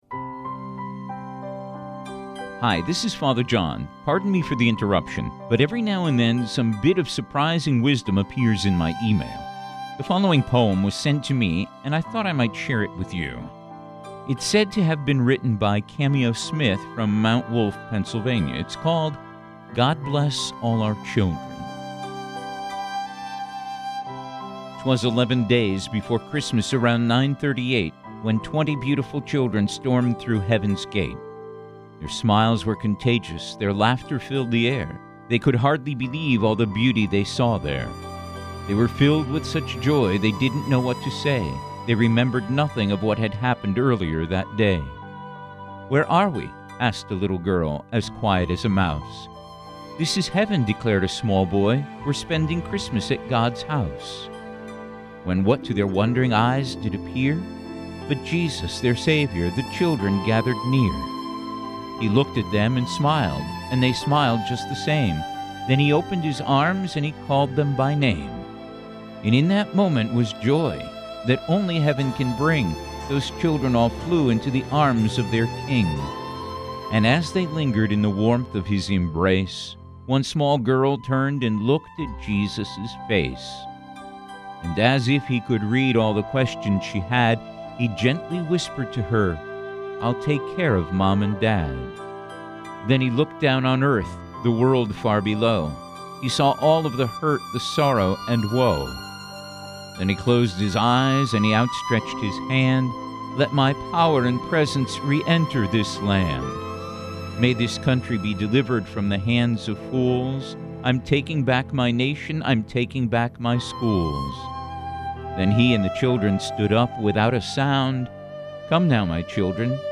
Mixed Poem with Manheim Mx.mp3